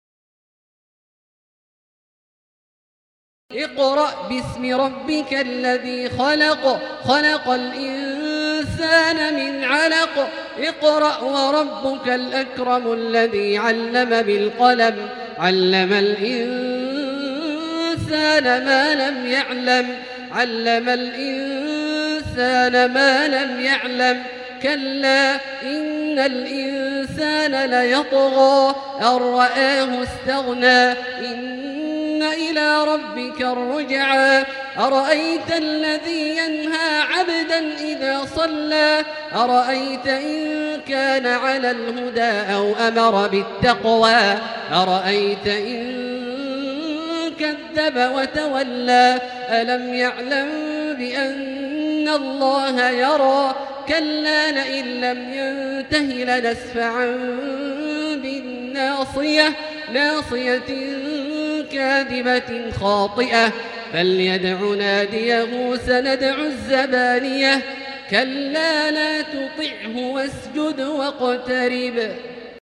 المكان: المسجد الحرام الشيخ: فضيلة الشيخ عبدالله الجهني فضيلة الشيخ عبدالله الجهني العلق